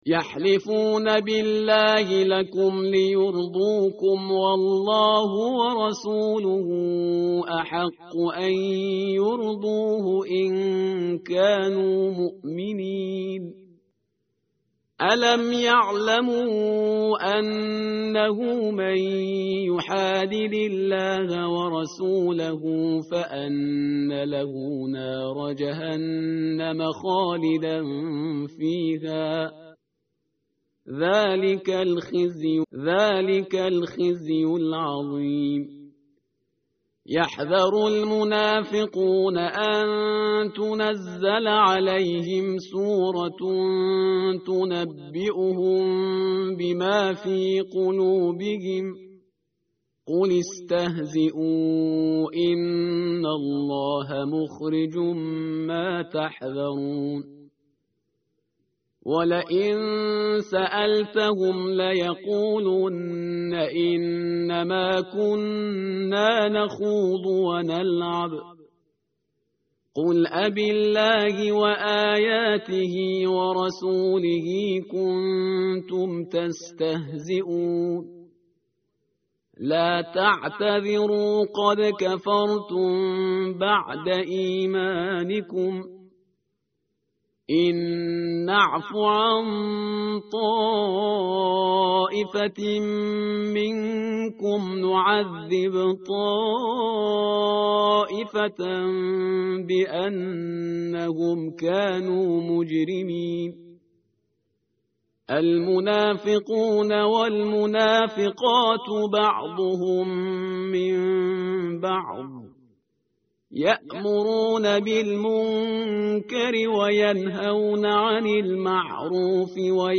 tartil_parhizgar_page_197.mp3